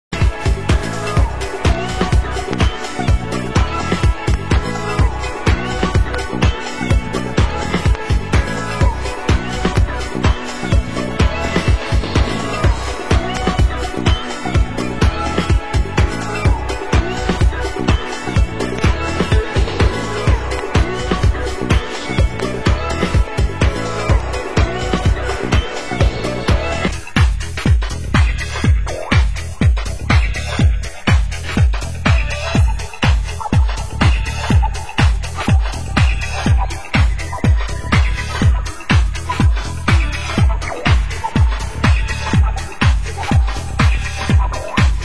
Genre: Deep House